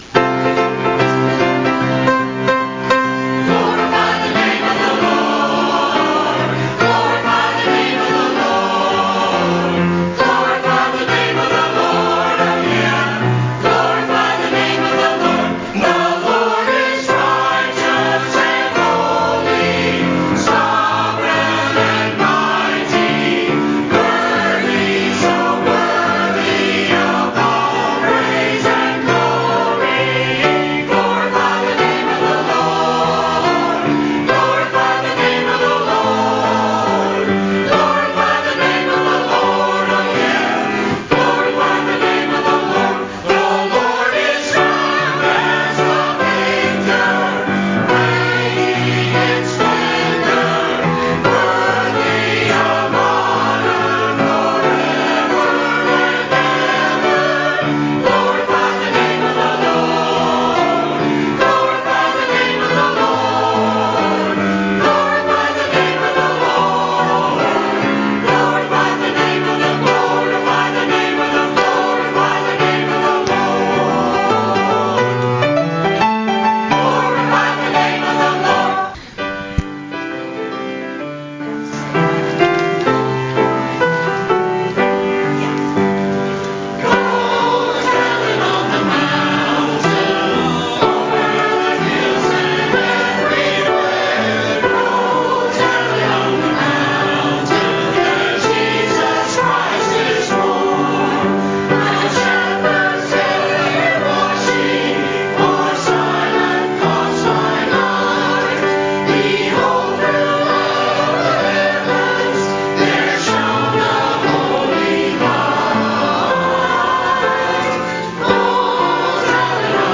January 8, 2017 – Music Sunday